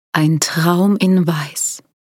Royalty free voices